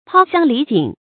抛乡离井 pāo xiāng lí jǐng
抛乡离井发音